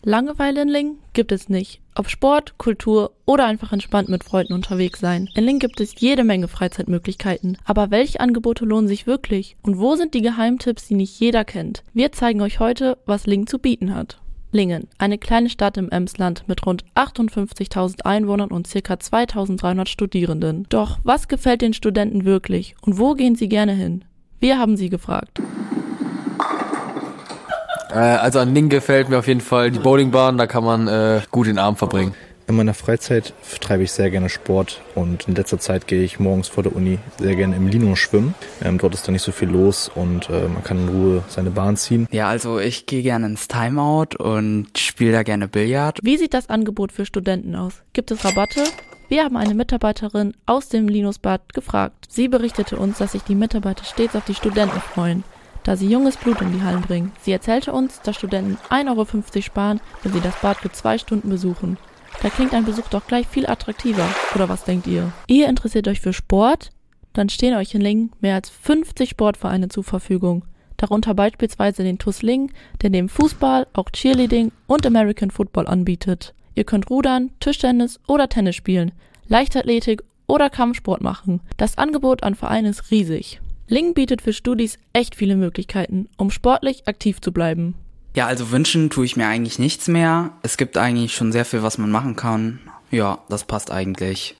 Im Interview: Studierende am Campus Lingen; Mitarbeiterin des Linus Bades